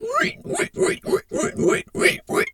pig_2_hog_seq_07.wav